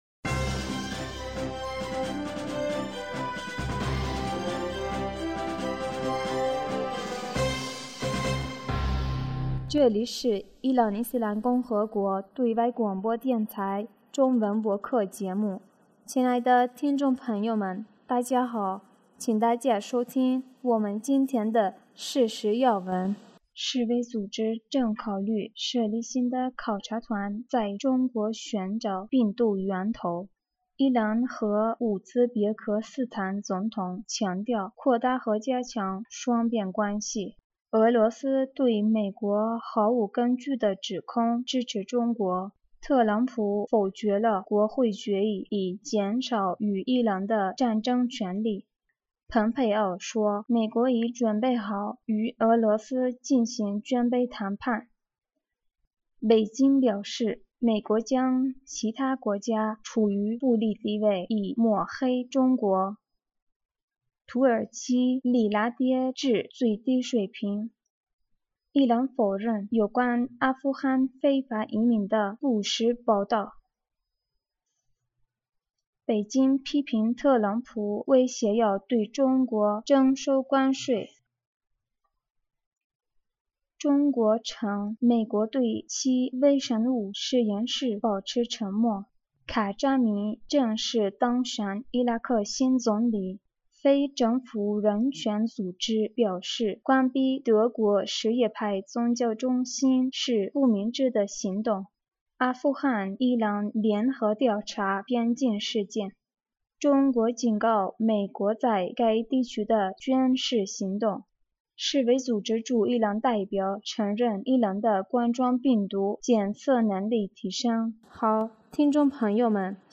2020年5月7日 新闻